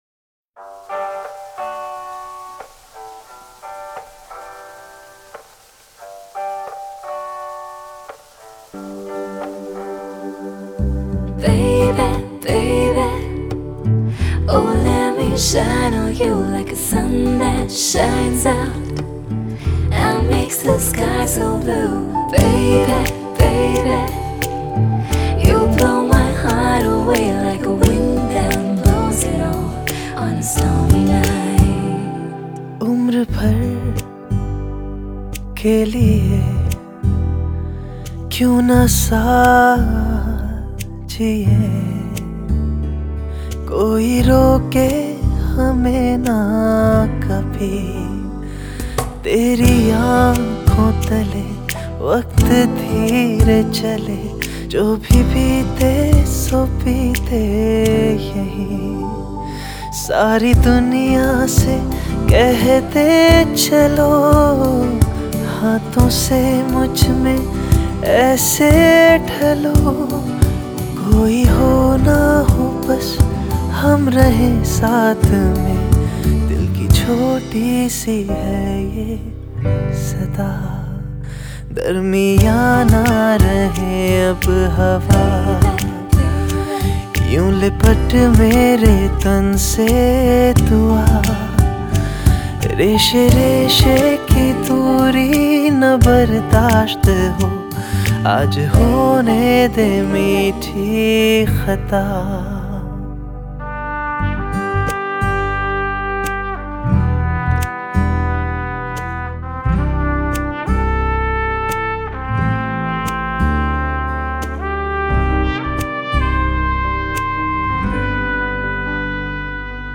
Bollywood Mp3 Music 2017